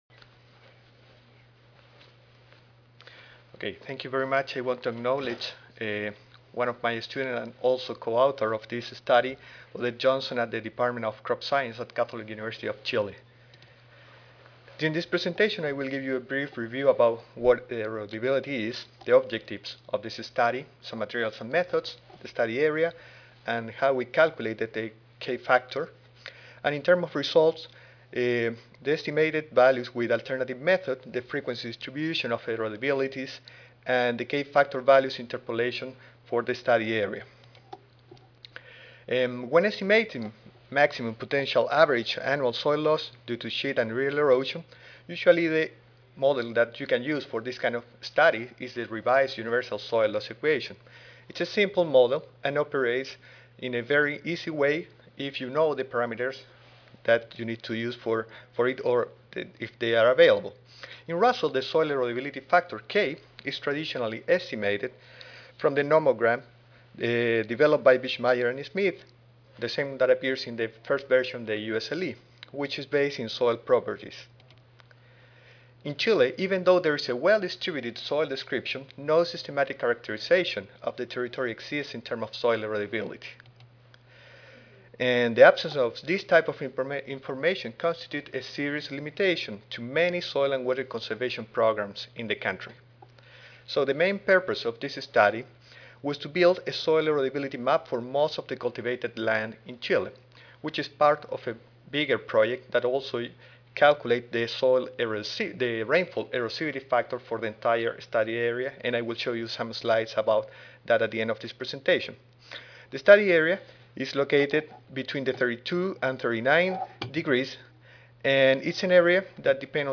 Católica de Chile Audio File Recorded presentation